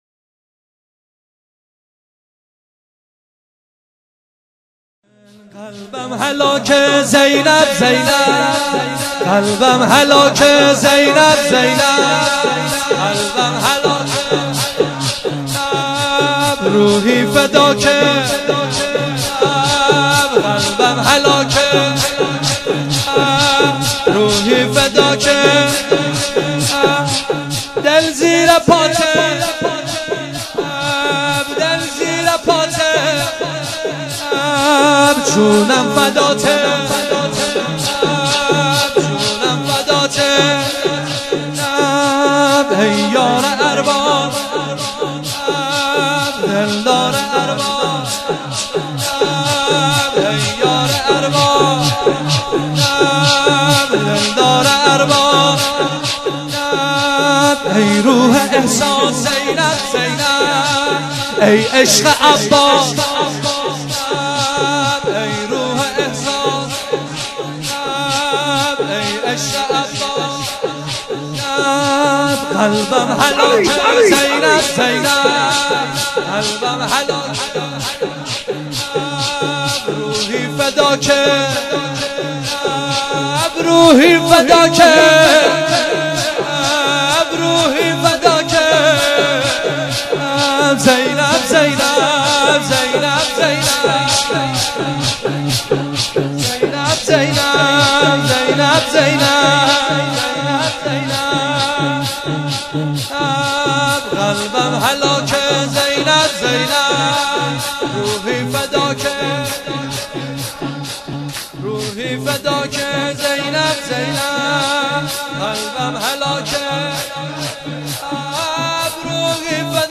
شور | قلبم هلاک زینب زینب
مداحی
جلسه هفتگی | شهادت حضرت زینب (سلام الله علیها)